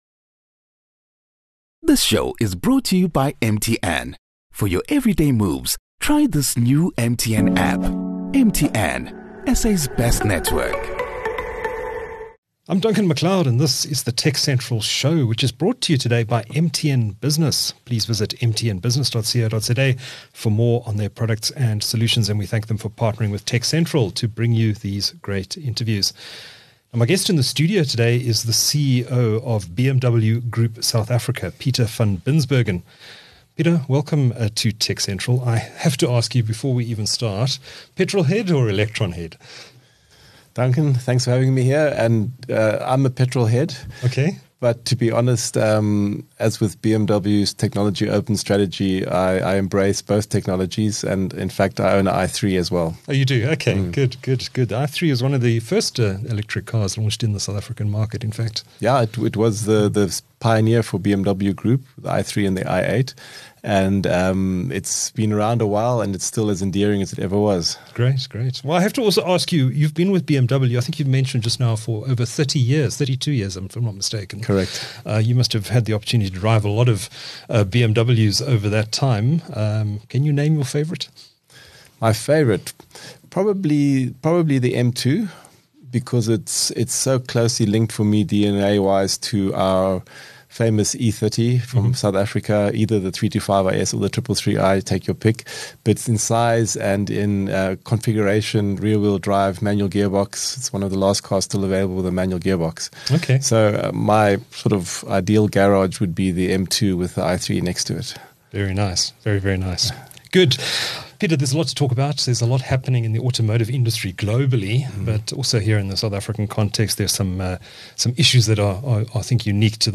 In the interview, TechCentral Show viewers will also hear about: